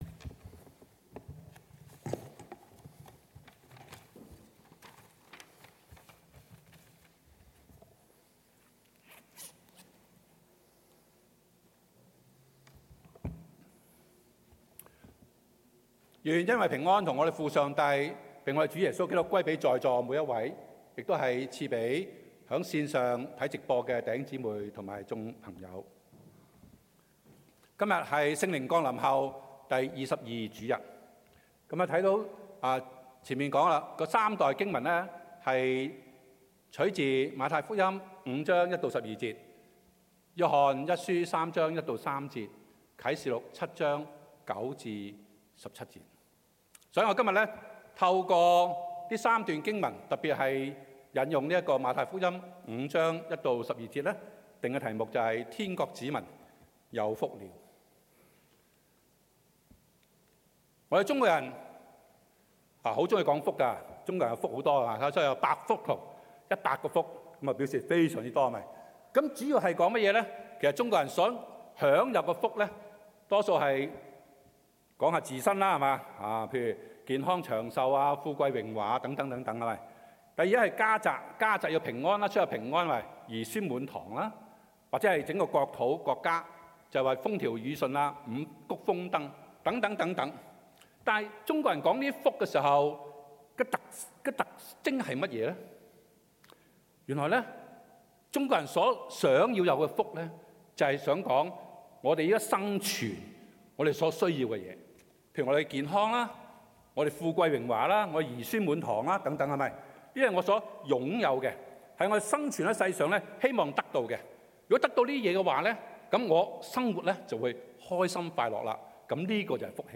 Sermon-Recording_6-Nov-22.mp3